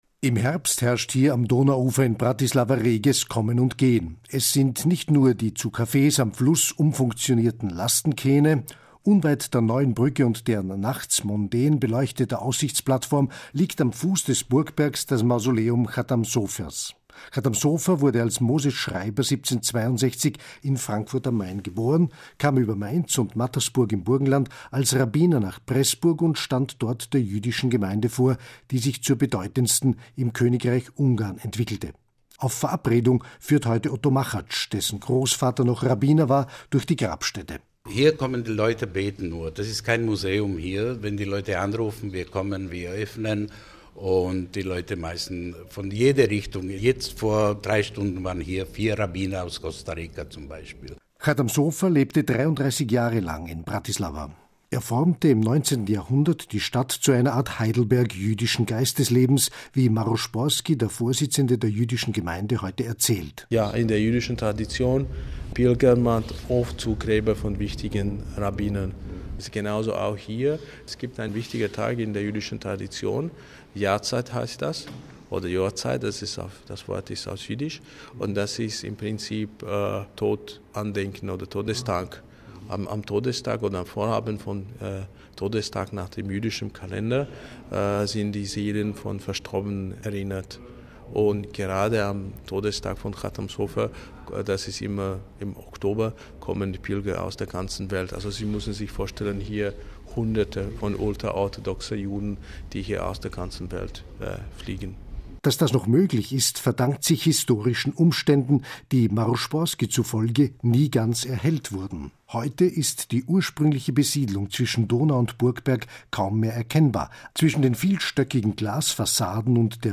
Der Radiobeitrag ist u.a. das Ergebnis einer Informationsreise nach Pressburg/Bratislava, die das Deutsche Kulturforum östliches Europa im September 2017 für Medienvertreter organisiert und begleitet hatte.